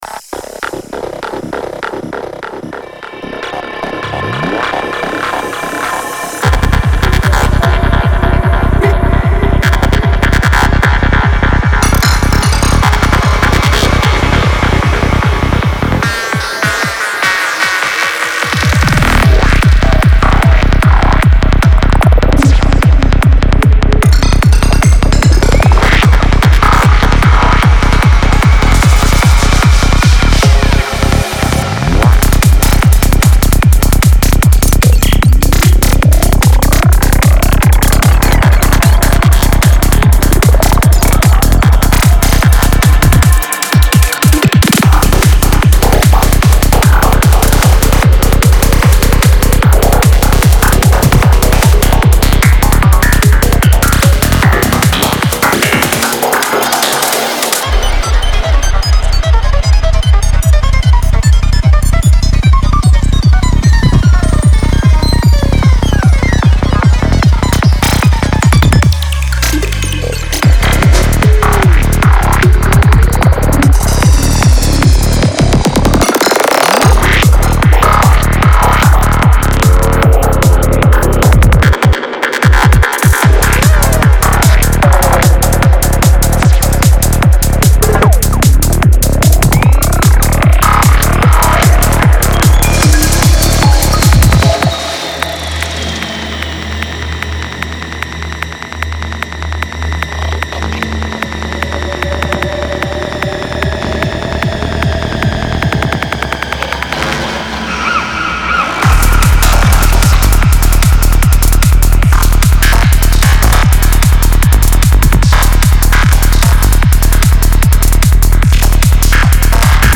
Genre:Psy Trance
すべて150〜155BPM、24bitの高音質で丁寧に制作されています。
デモサウンドはコチラ↓